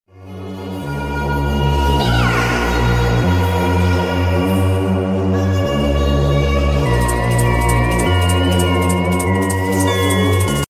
A sonic boom is a sound effects free download
A sonic boom is a sound associated with the shock wave that is created when an object travels through air faster than the speed of sound . Sonic booms produce huge amounts of sound energy, which sounds like an explosion or thunder to the human ear.